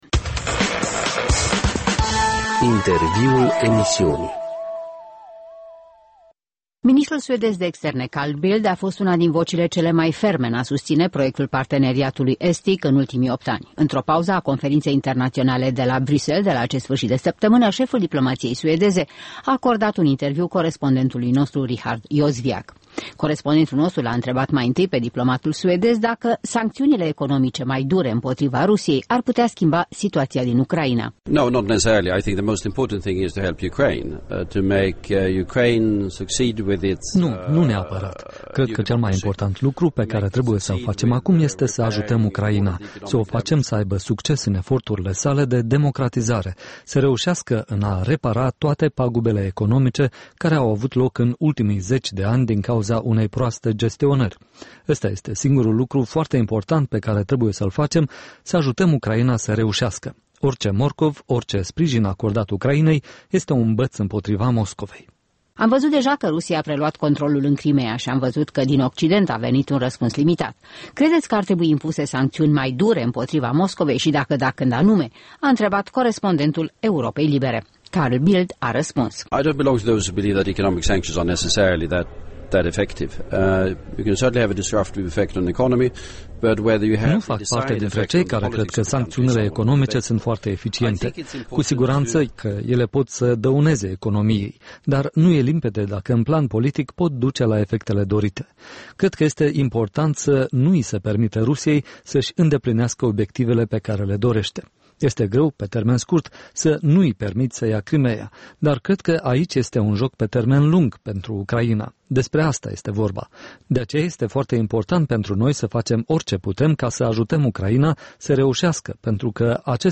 Carl Bildt, ministrul de externe al Suediei, răspunde întrebărilor Europei Libere